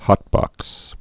(hŏtbŏks)